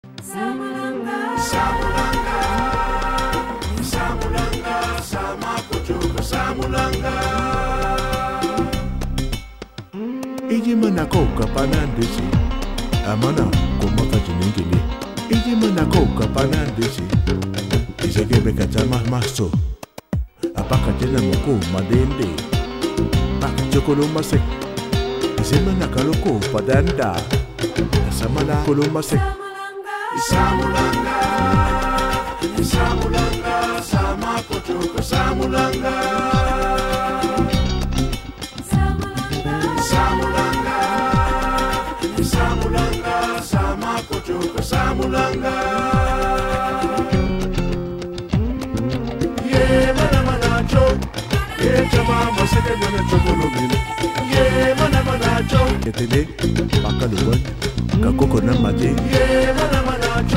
Genre: world
Upbeat track with traditional percussion   3:35